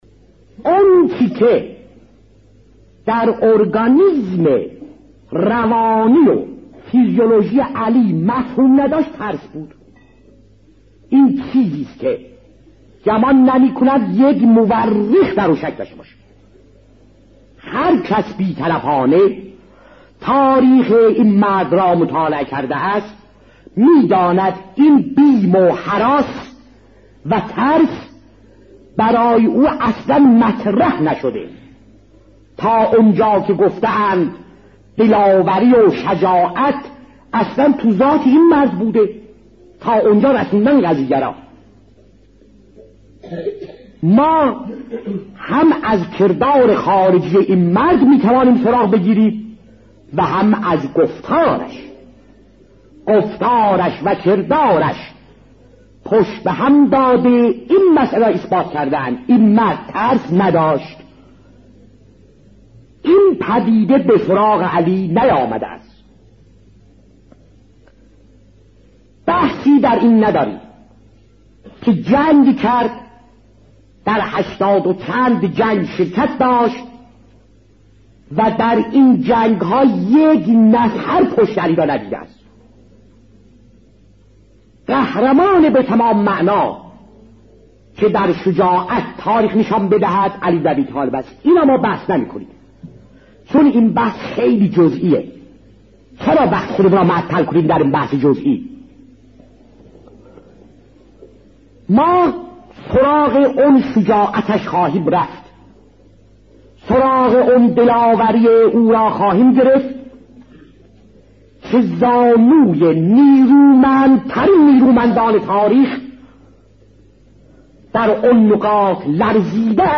علامه محمدتقی جعفری(ره) در یکی از سخنرانی‌هایش درباره شجاعت و دلاوری امیرالمؤمنین(ع) می‌گوید: آنچه که در اورگانیزم روانی فیزیولوژی امیرالمؤمنین(ع) مفهوم نداشت ترس بود؛ گمان نمی‌کنم مورخی در آن شک داشته باشد. هر کس بی‌طرفانه تاریخ این مرد را مطالعه کرده است می‌داند ترس، بیم و هراس برای او مطرح نشده است، تا آنجا که گفته‌اند دلاوری و شجاعت در ذات این بزرگ مرد وجود دارد.